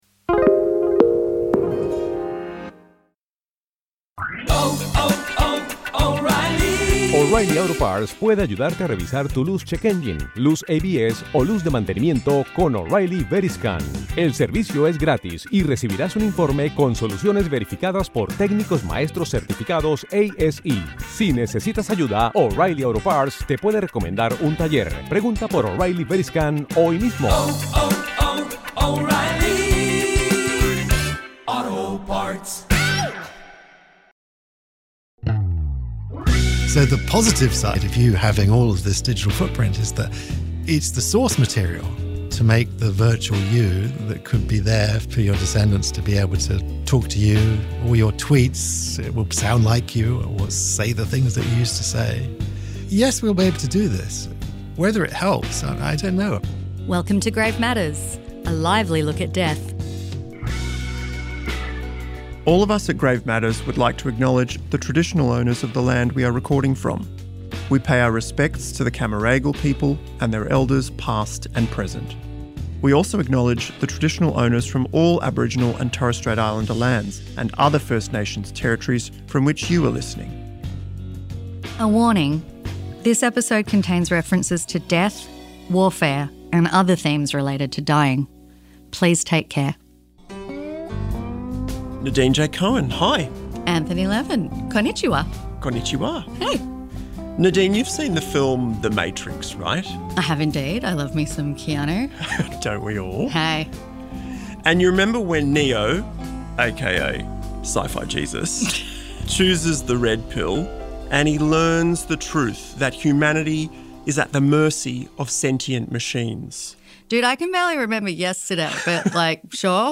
AI expert, Professor Toby Walsh